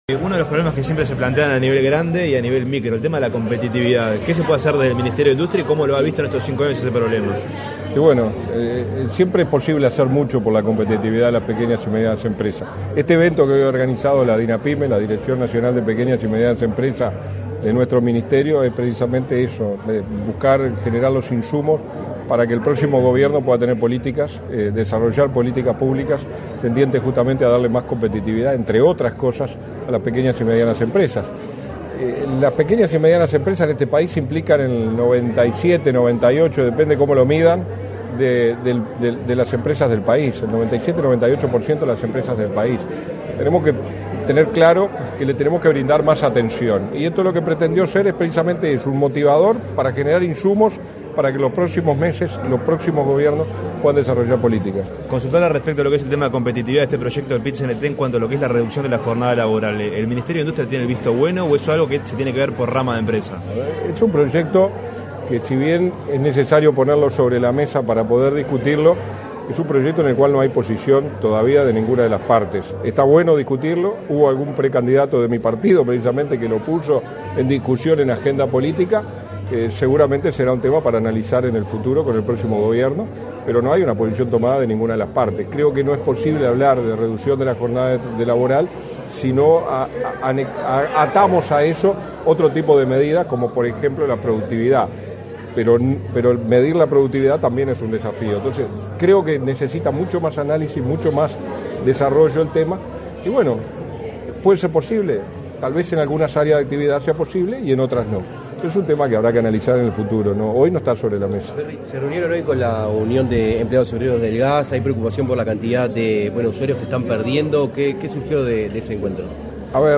Declaraciones a la prensa del subsecretario de la cartera, Walter Verri
Declaraciones a la prensa del subsecretario de la cartera, Walter Verri 22/08/2024 Compartir Facebook X Copiar enlace WhatsApp LinkedIn Tras participar en la 1.° edición del Foro Mipyme Uruguay, organizado por el Ministerio de Industria, Energía y Minería (MIEM), este 21 de agosto, el subsecretario de la cartera, Wlater Verri, realizó declaraciones a la prensa.